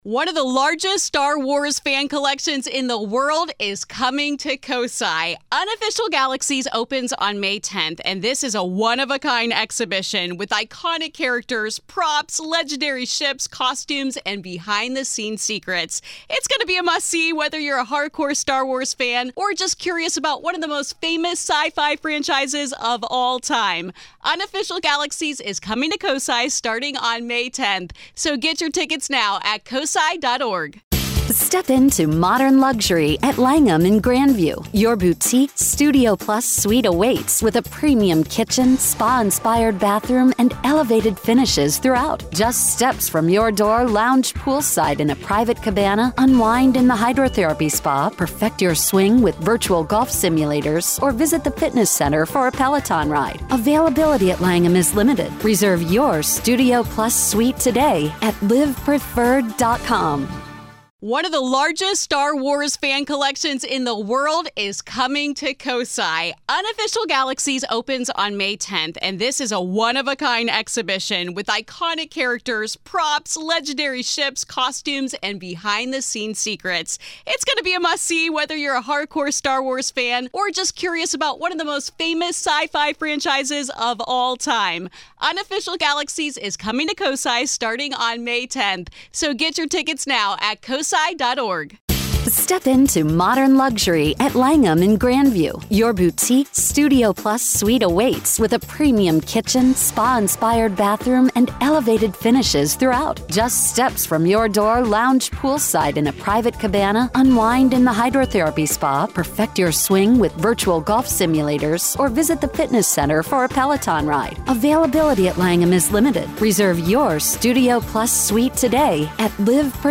This is Part One of our conversation.